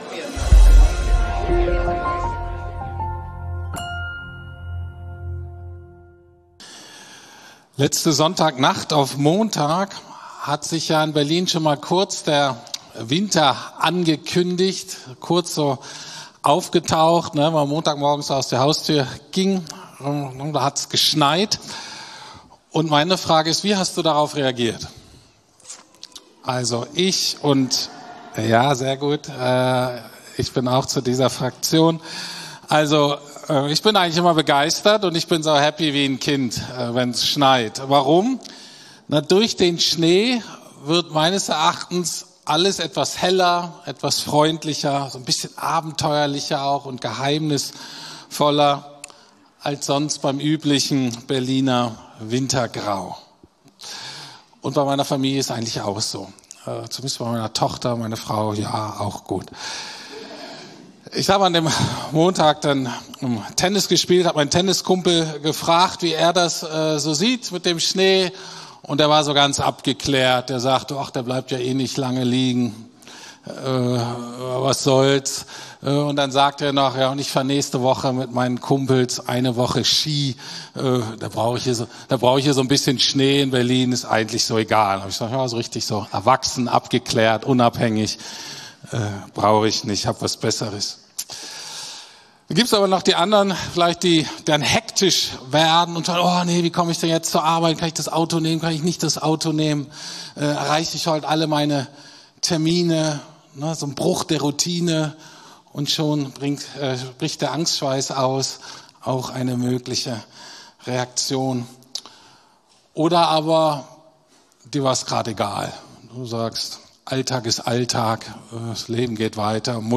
Apokalypse 2025-Gott zeigt sich durch Gnade ~ Predigten der LUKAS GEMEINDE Podcast